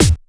Kick_19.wav